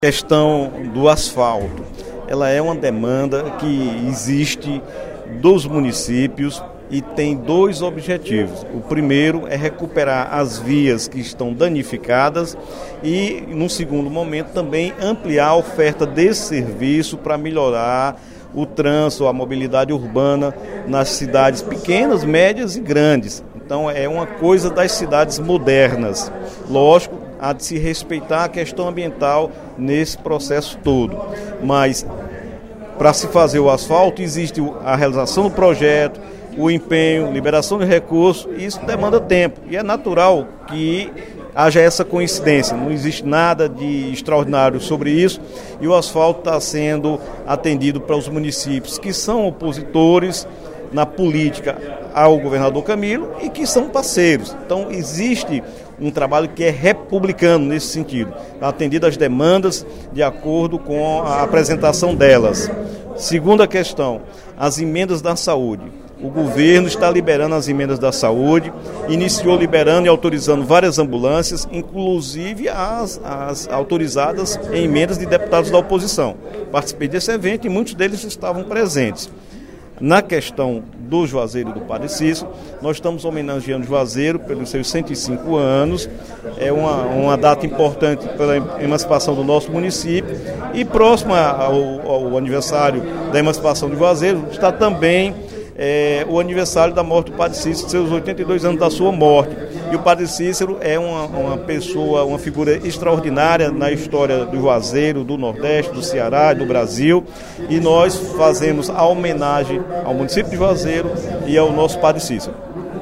O deputado Dr. Santana (PT) comentou, no primeiro expediente da sessão plenária desta quarta-feira (13/07), a necessidade da liberação pelo Governo do Estado de R$ 86 milhões para a pavimentação asfáltica em municípios cearenses.